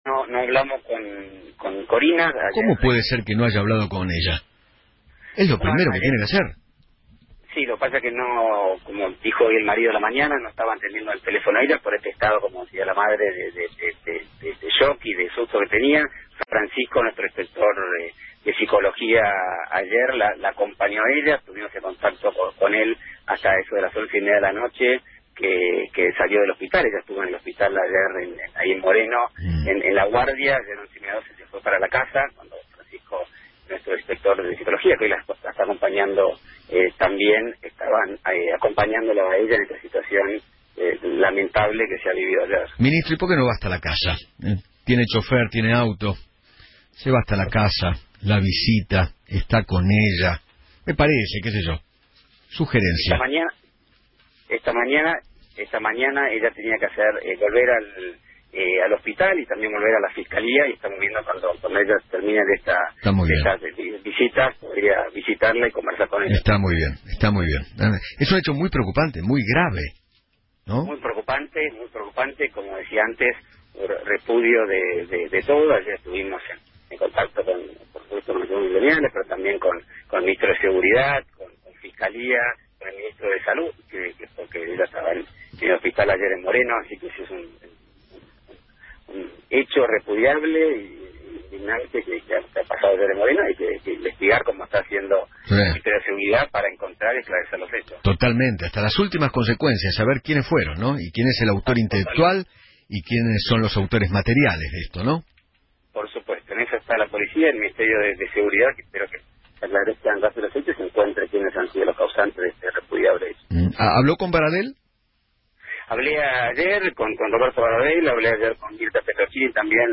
Eduardo Feinmann, conductor: “Cómo no habló… era lo primero que debía hacer”